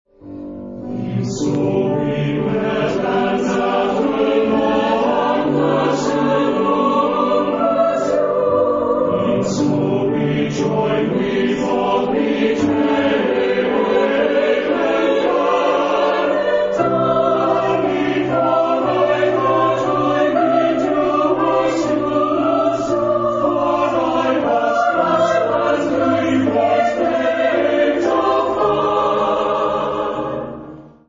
Genre-Stil-Form: weltlich ; Motette ; Ballade
Charakter des Stückes: beständig ; sanft
Chorgattung: SATB  (4 gemischter Chor Stimmen )
Instrumentation: Streichorchester ODER Klavier